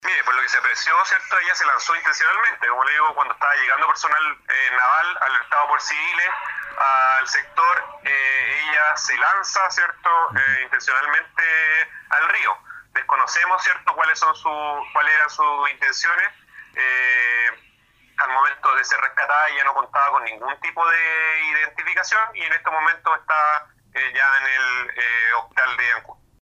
El oficial de la Armada indicó que según los antecedentes recogidos en el lugar, esta persona efectivamente tenía la intención de lanzarse a las aguas del río Pudeto, desconociendo hasta ahora, cuáles eran sus razones para esta acción.